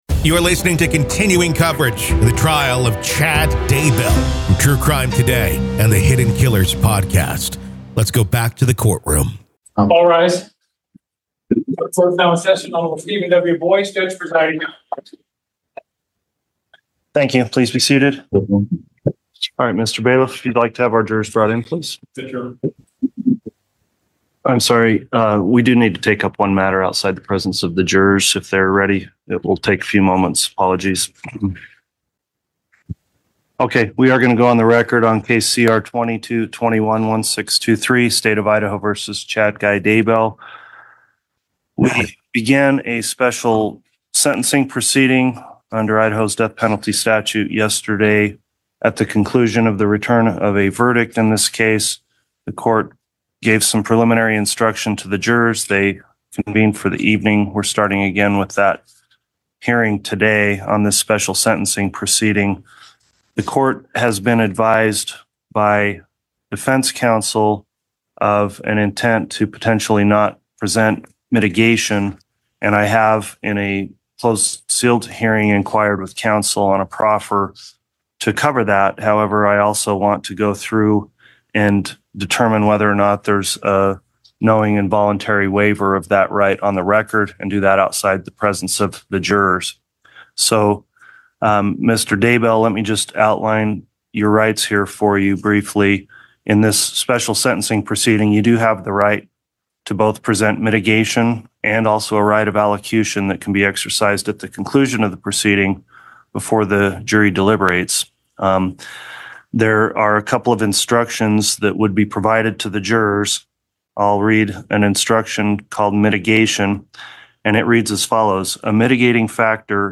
During Friday's session, emotional testimonies from the victims' families were anticipated, aiming to provide the jury with a deeper understanding of the devastating impact of Daybell's actions.